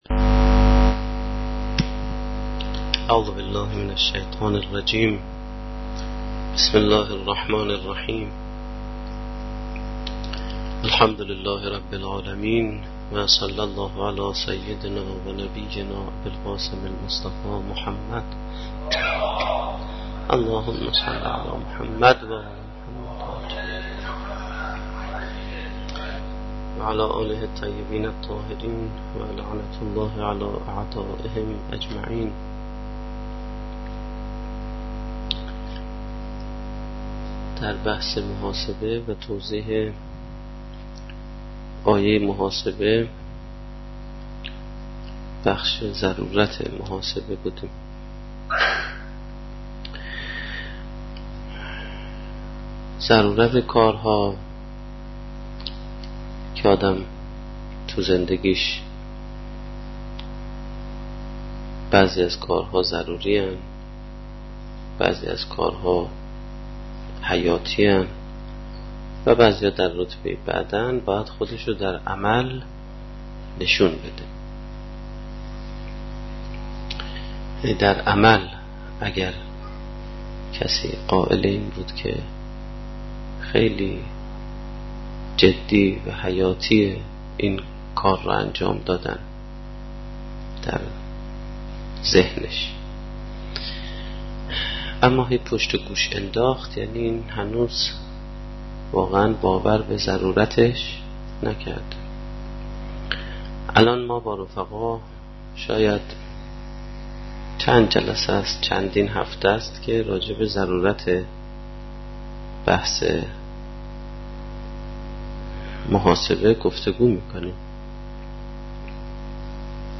سخنرانی
در جلسه سیر و سلوک قرآنی